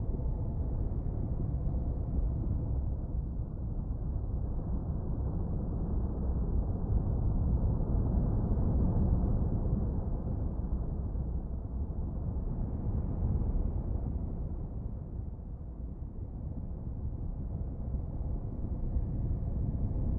WAV · 1.7 MB · 立體聲 (2ch)